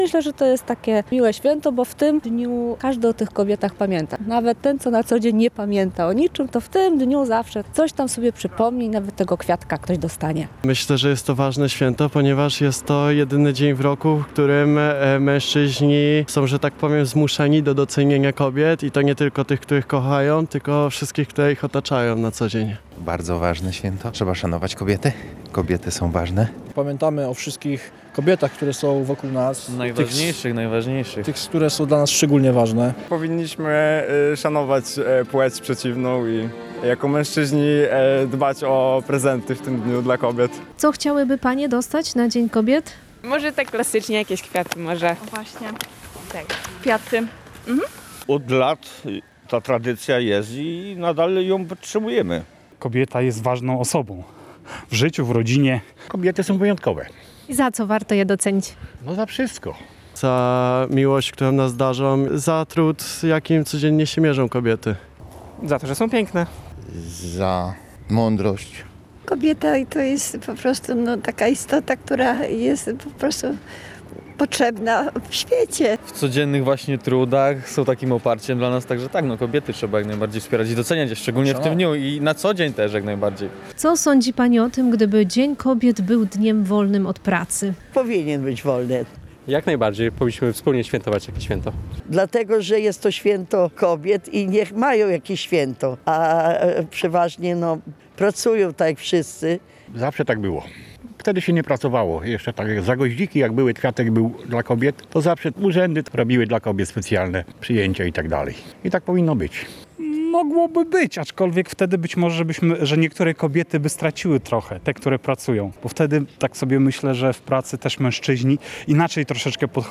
O zdanie zapytaliśmy mieszkańców Rzeszowa.